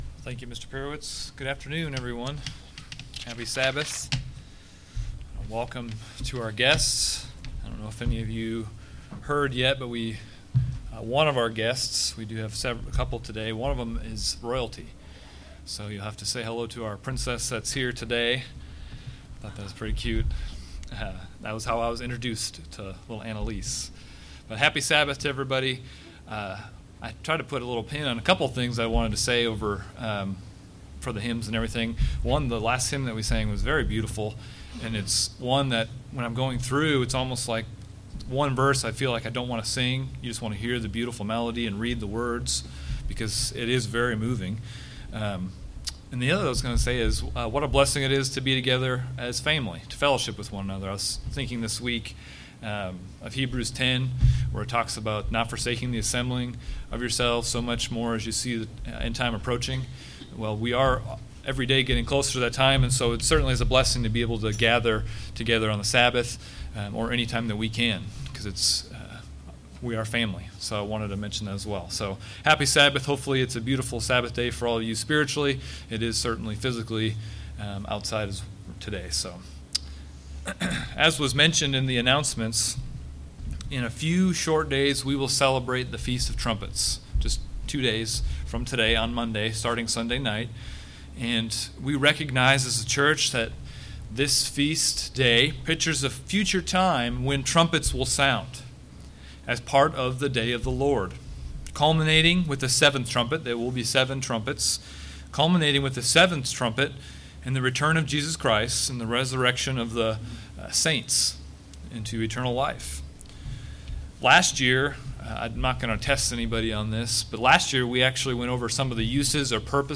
God's word is timeless however and as such as a Church today, what can we learn from the book of Habakkuk? In this sermon, the speaker looks into the book of Habakkuk and pulls out several warnings that we as Christians need to heed.